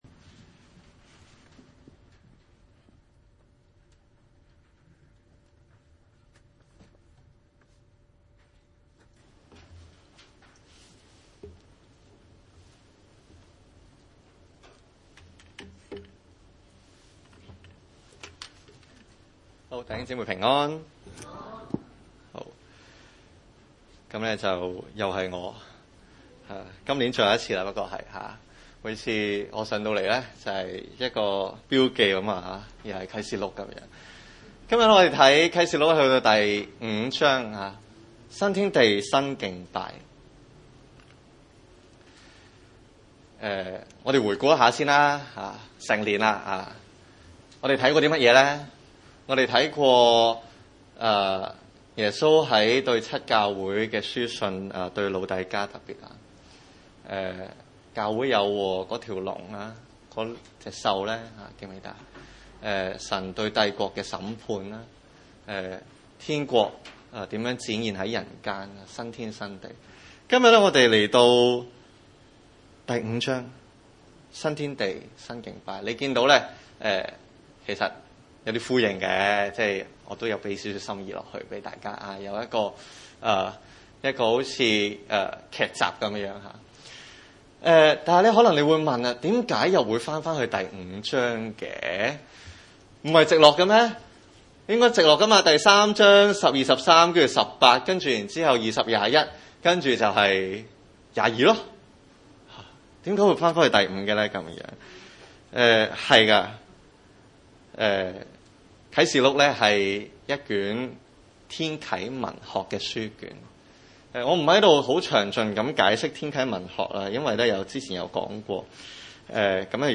啟示錄5章1-14節 崇拜類別: 主日午堂崇拜 1 我看見坐寶座的右手中有書卷，裏外都寫着字，用七印封嚴了。